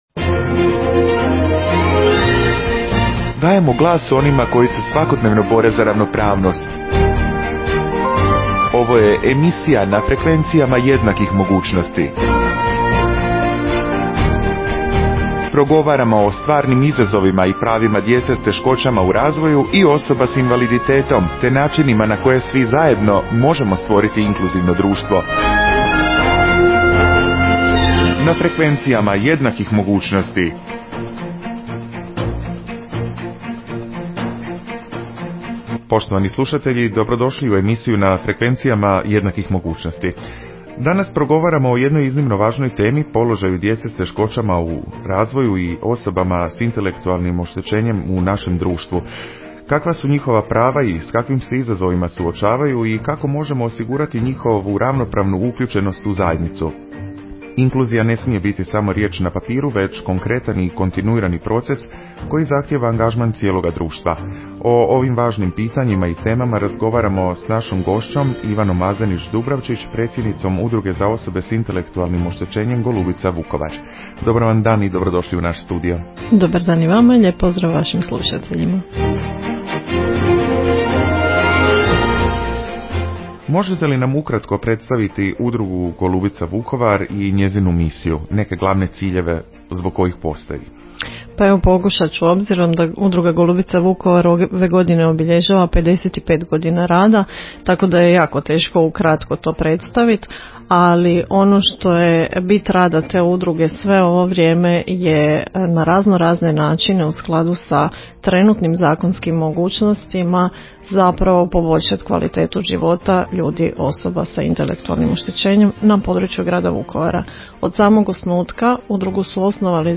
U današnjoj emisiji razgovaramo o Udruzi za osobe s intelektualnim oštećenjem Golubica Vukovar, koja će ove godine obilježiti 55 godina predanog rada. Gošća nam je